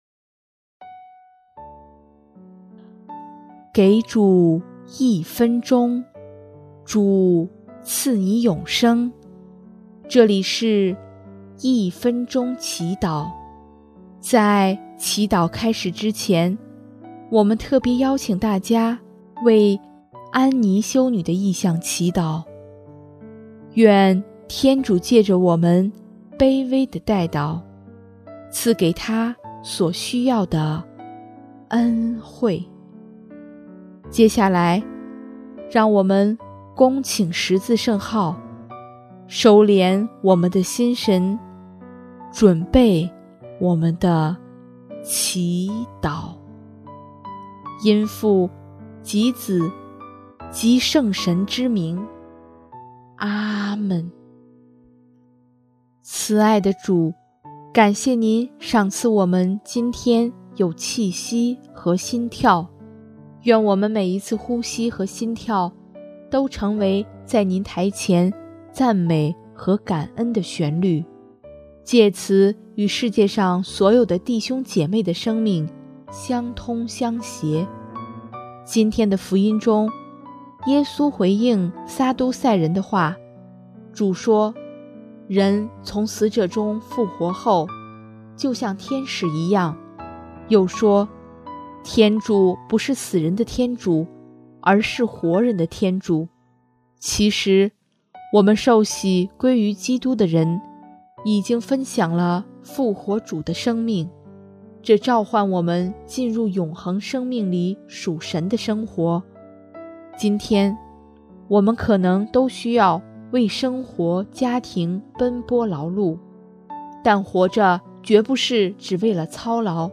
音乐：第三届华语圣歌大赛参赛歌曲《主，爱你》